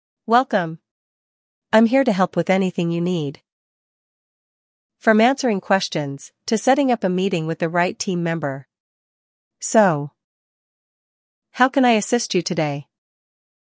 Human Like AI Voice
VerbaCall-AI-Agent-Female-2.mp3